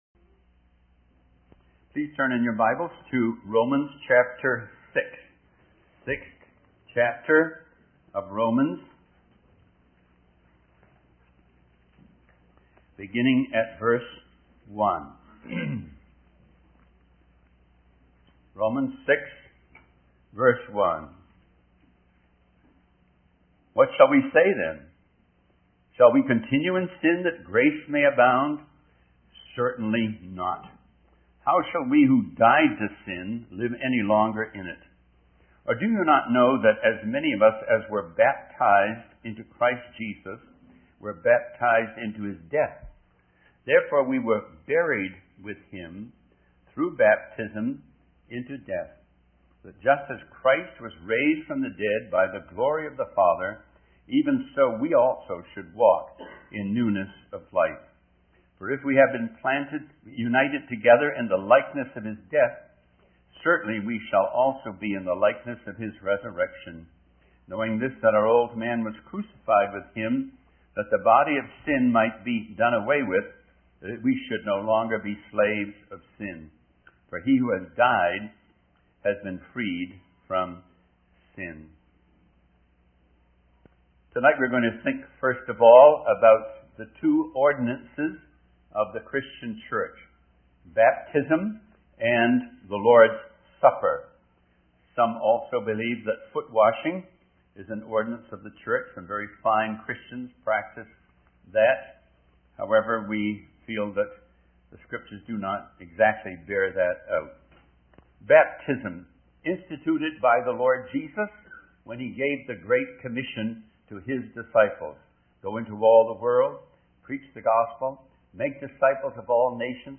In this sermon, the speaker emphasizes the importance of preparing for the Lord's Supper by meditating on the Lord and not appearing before God empty.